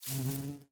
Minecraft Version Minecraft Version latest Latest Release | Latest Snapshot latest / assets / minecraft / sounds / mob / bee / pollinate1.ogg Compare With Compare With Latest Release | Latest Snapshot
pollinate1.ogg